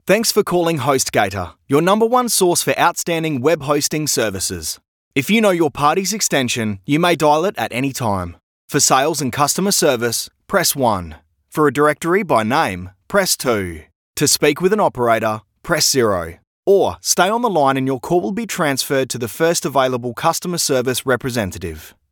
Male
English (Australian)
Adult (30-50)
My voice although very versatile has been described as warm, engaging, Aussie slang, natural, professional, confident, strong, professional and friendly to name a few.
Phone Greetings / On Hold
1203On_Hold_Script.mp3